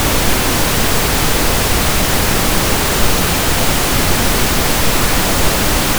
Visualisierung und Auralisierung der Ergebnisse